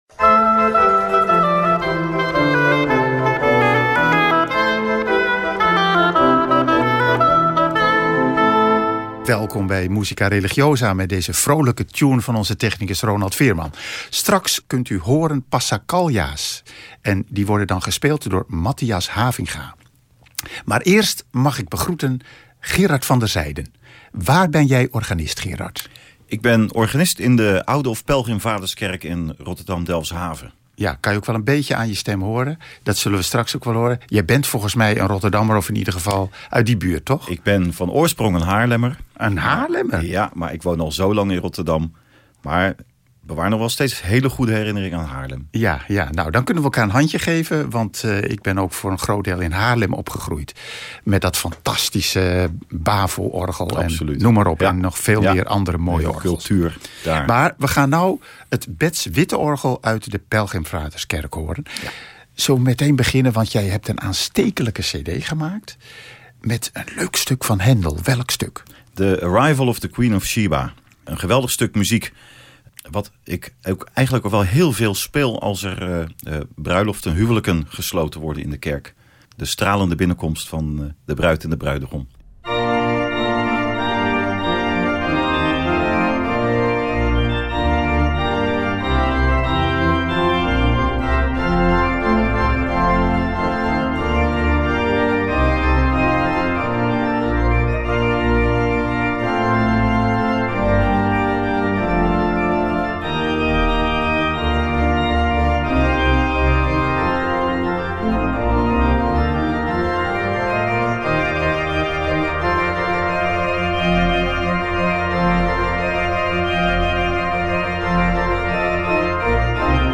Dit programma is al een aantal jaren geleden opgenomen.